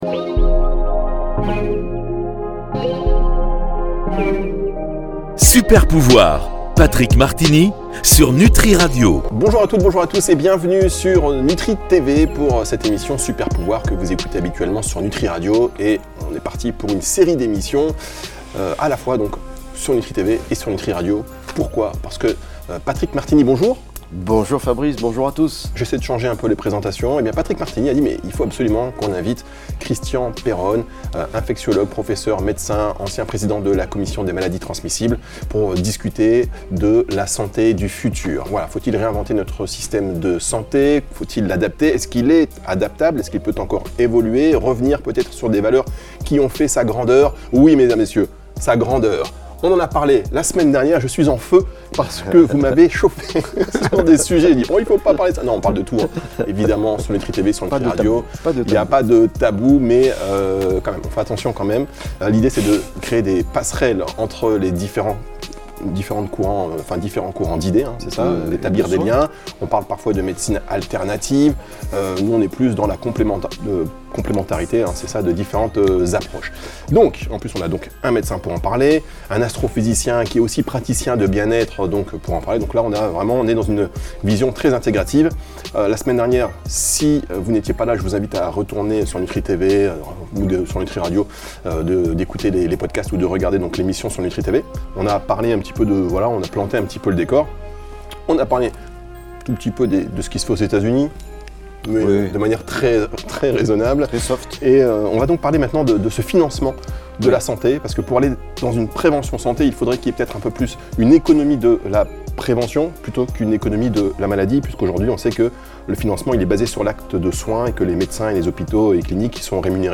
Invité: Christian Perronne. Comment redéfinir le système de santé. Part II